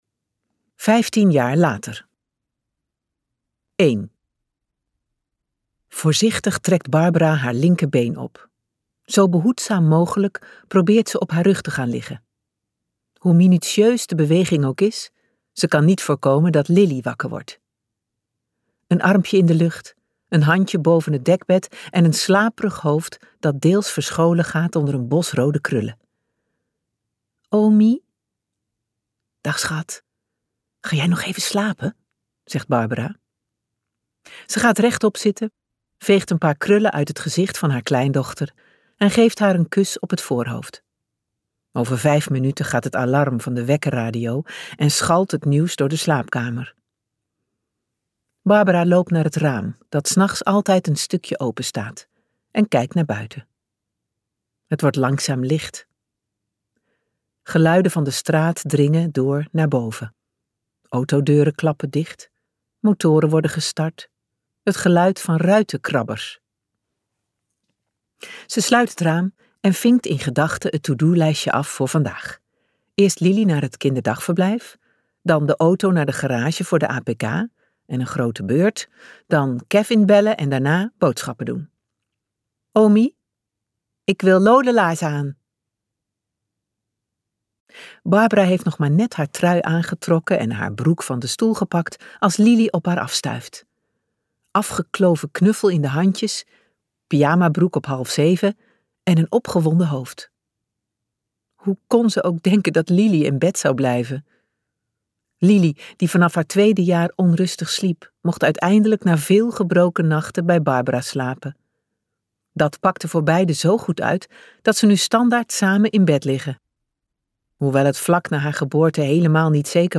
Ambo|Anthos uitgevers - Kwaad bloed luisterboek